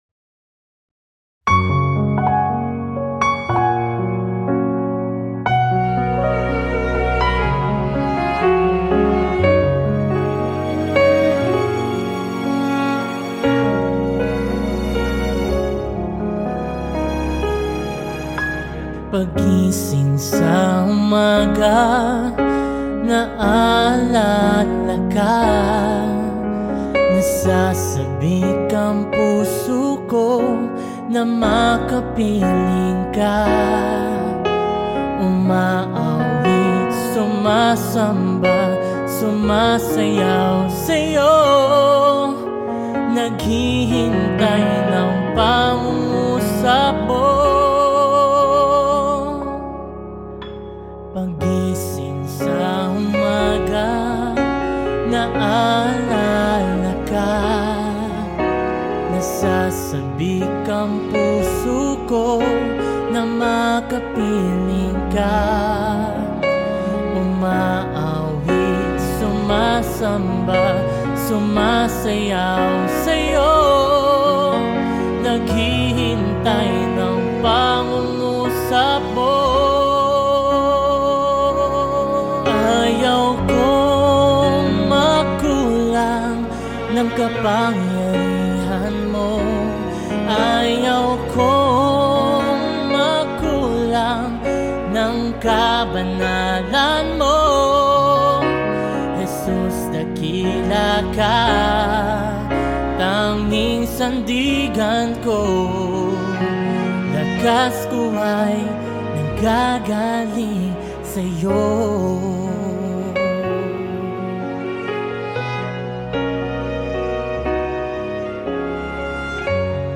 62 просмотра 76 прослушиваний 0 скачиваний BPM: 121